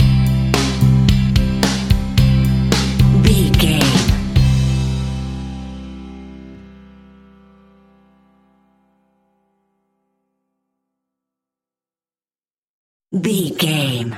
Ionian/Major
calm
happy
smooth
uplifting
electric guitar
bass guitar
drums
pop rock
indie pop
organ